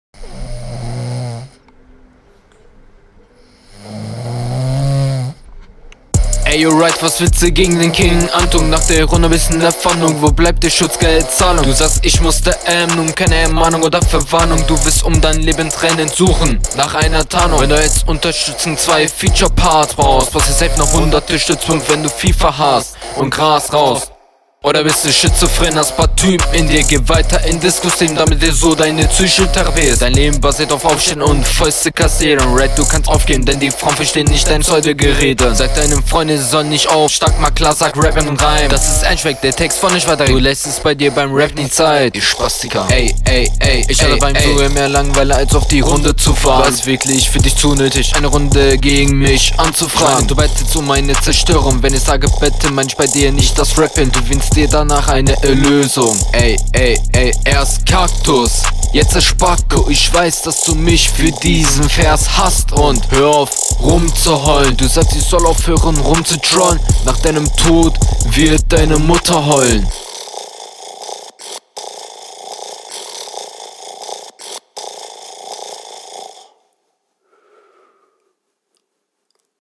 Im Vergleich zum Gegner flowst du relativ unsicher - teilweise ziemlich offpoint.